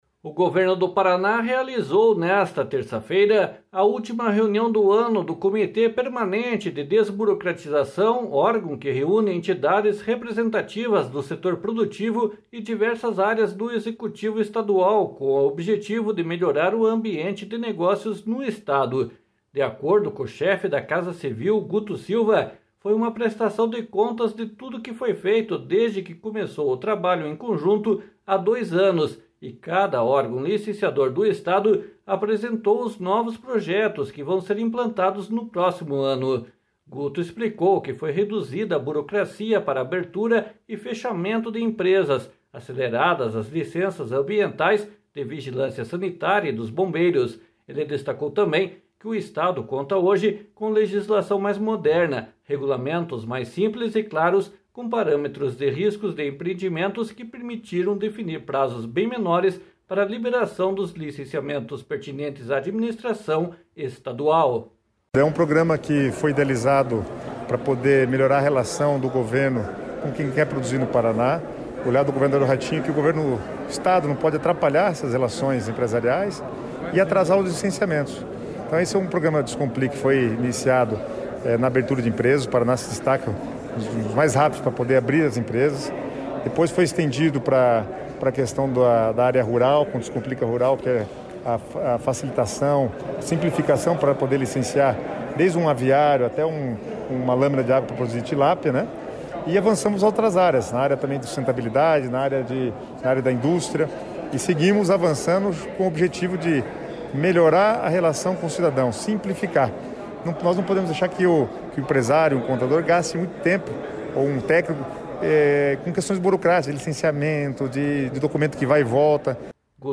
//SONORA GUTO SILVA//